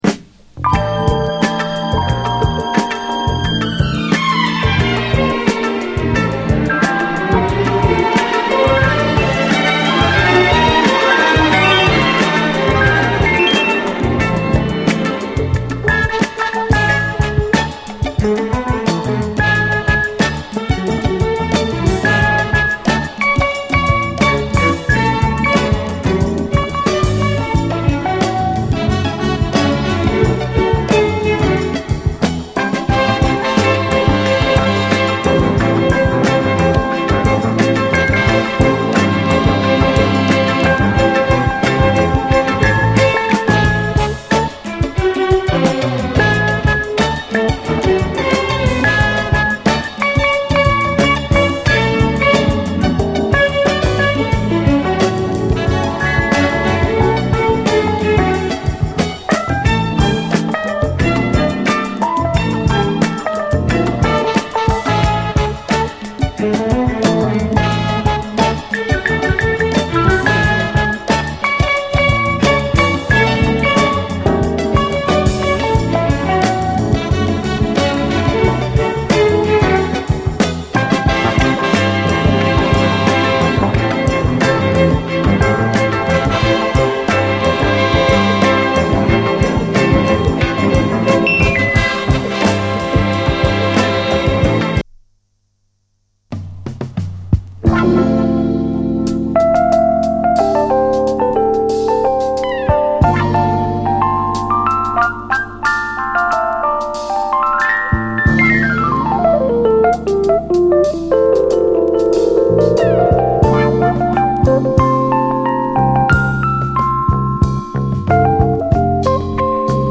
JAZZ FUNK / SOUL JAZZ, JAZZ
エレピが美しいネタモノ・ジャズ・ファンク名盤！